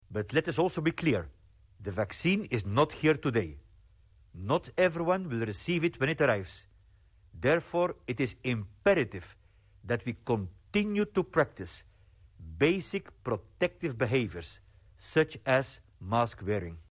Hans Kluge, directorul OMS Europa: